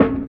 87 FILT SNR.wav